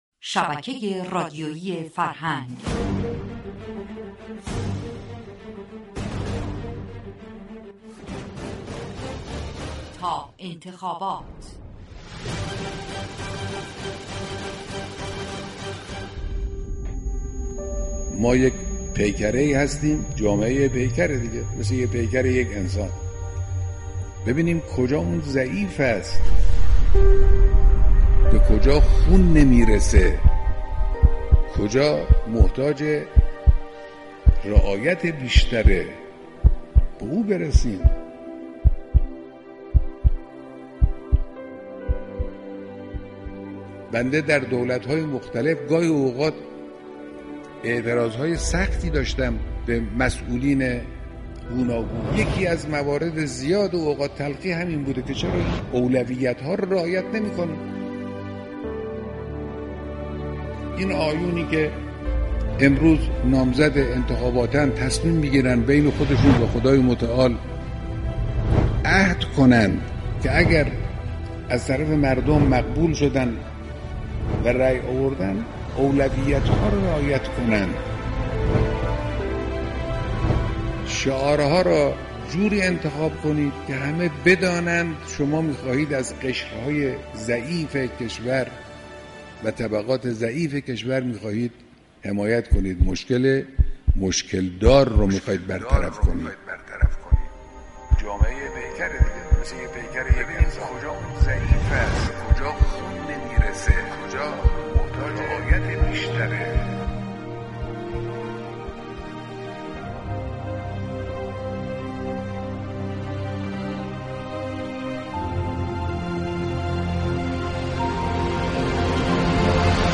(فایل صوتی مربوط به گزیده ی سخنان مقم معظم رهبری را از لیك پایین صفحه دانلود كنید .)